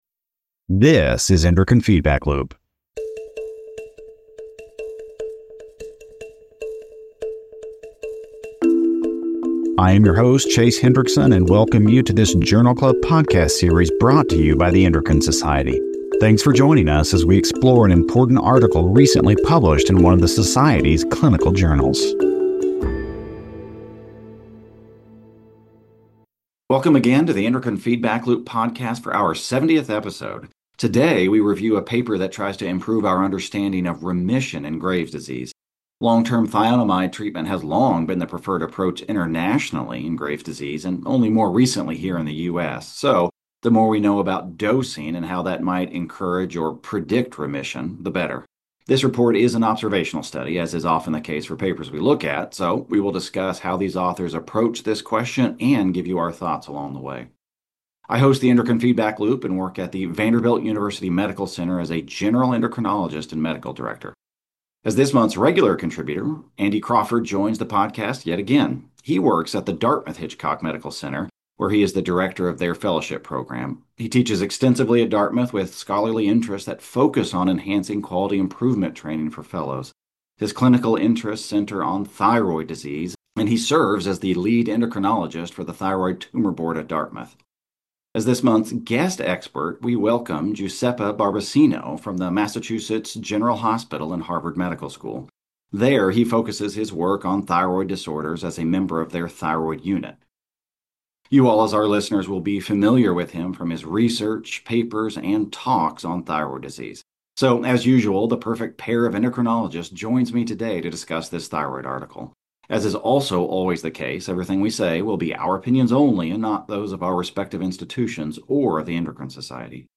Meet the Speakers